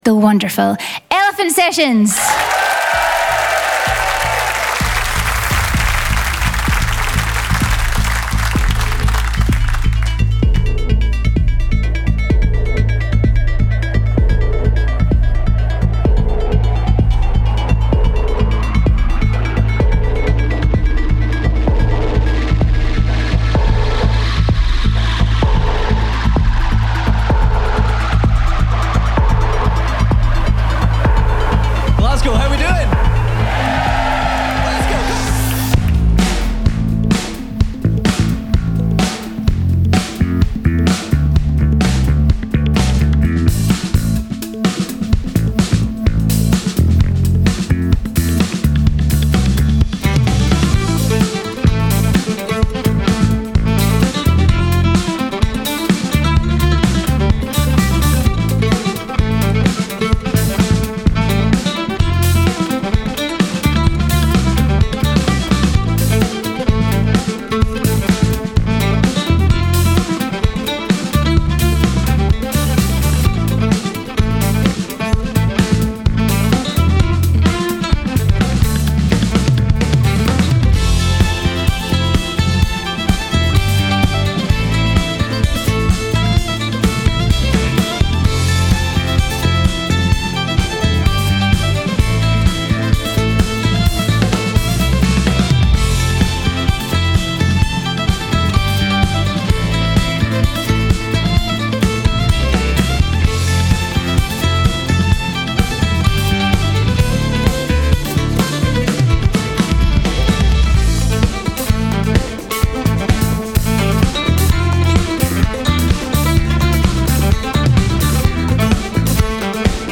Celtic Alt/new Folk
fiddle
mandolin
drums, samples